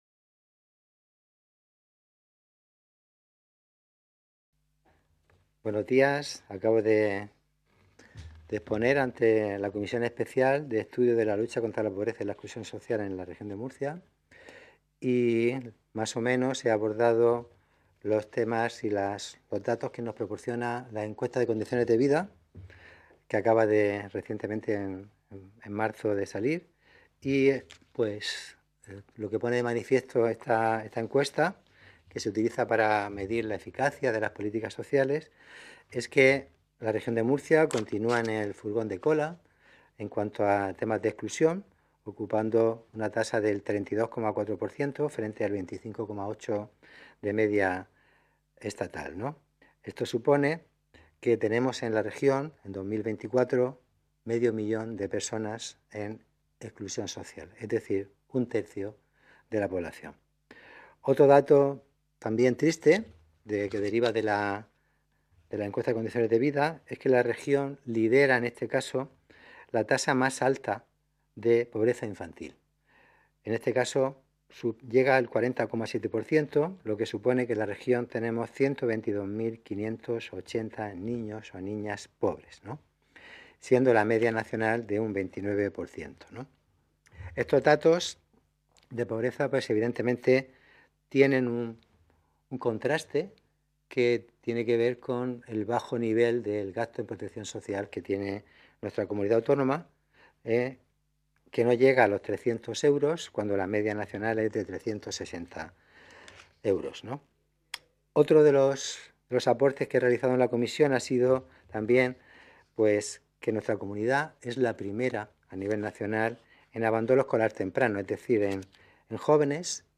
Ruedas de prensa tras la Comisión Especial de Estudio de Lucha contra la Pobreza y la Exclusión Social en la Región de Murcia